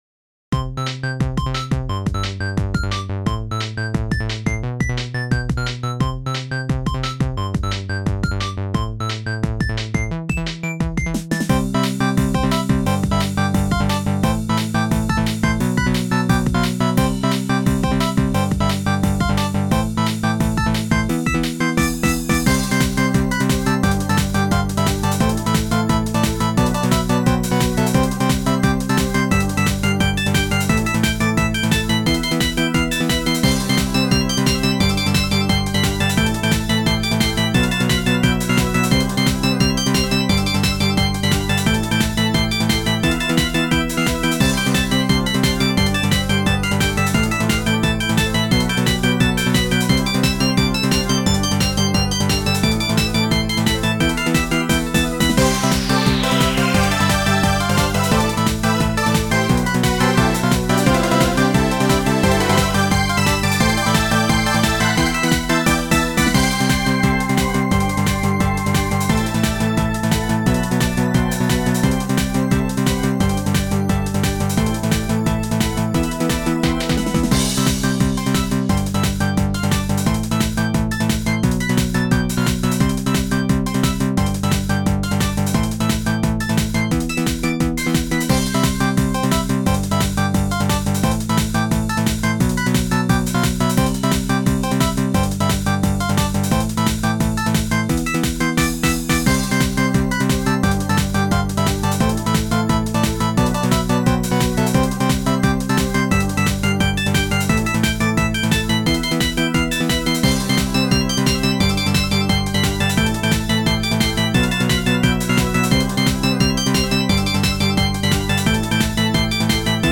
ジャンルTechno(Synth.?)